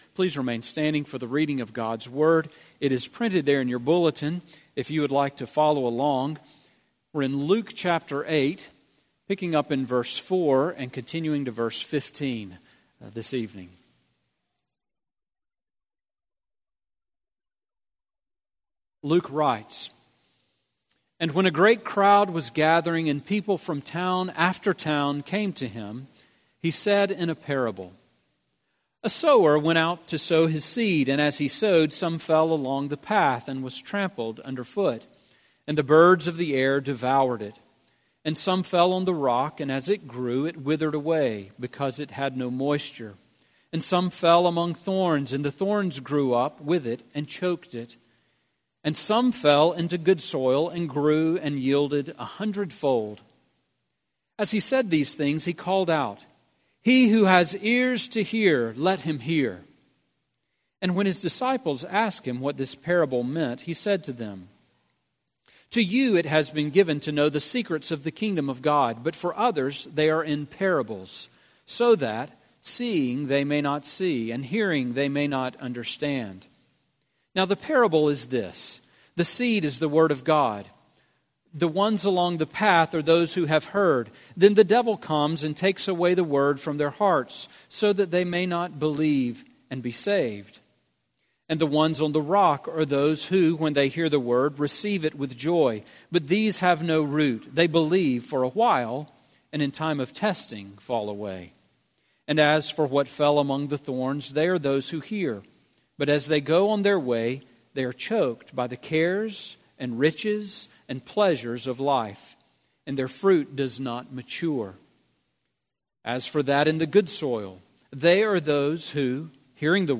Sermon on Luke 8:4-15 from October 8